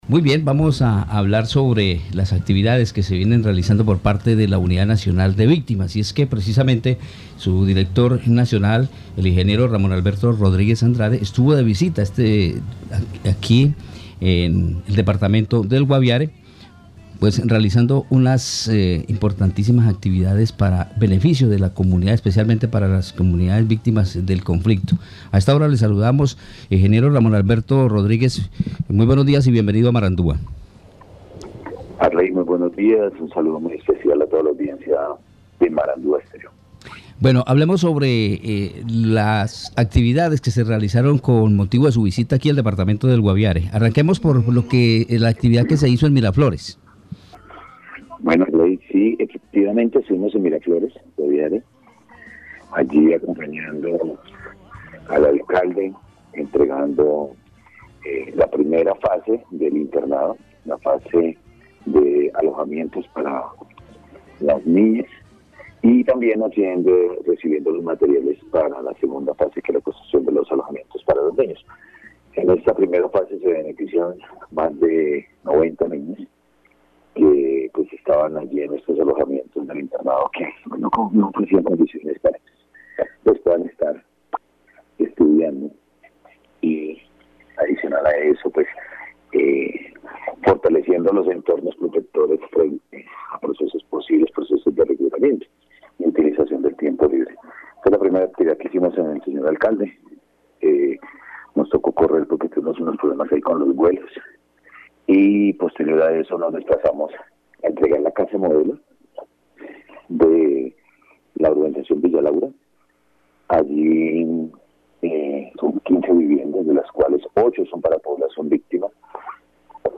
Escuche a Ramón Rodríguez, director General para la Unidad de la Víctimas.